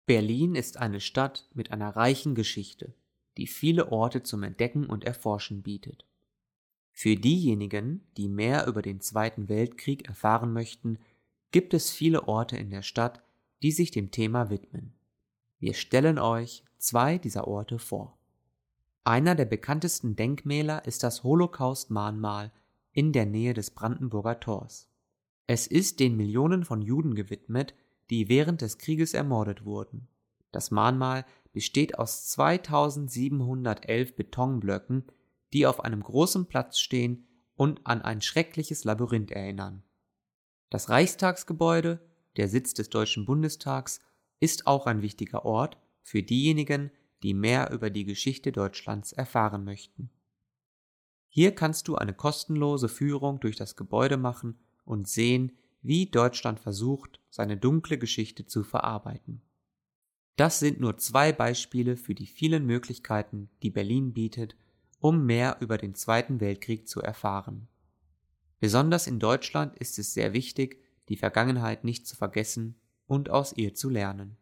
german-reading-world-war-2-berlin_jufqs6.mp3